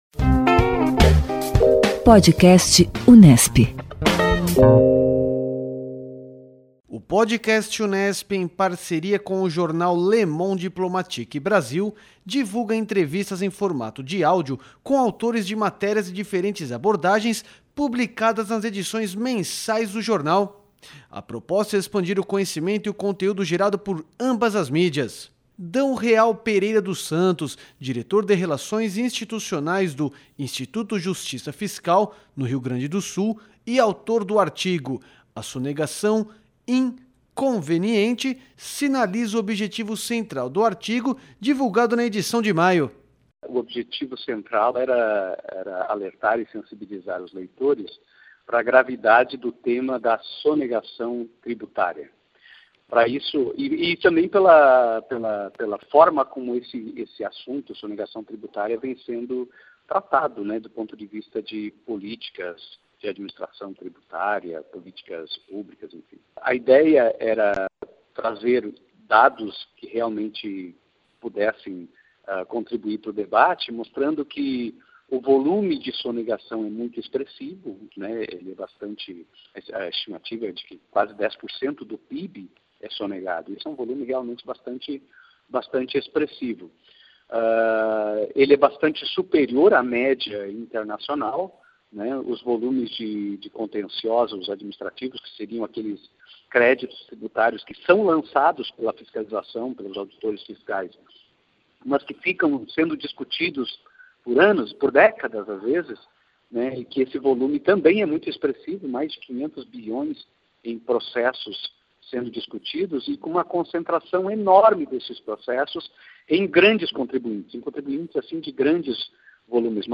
O Podcast Unesp em parceria com o jornal Le Monde Diplomatique Brasil divulga entrevistas em formato de áudio com autores de matérias de diferentes abordagens, publicadas nas edições mensais do jornal.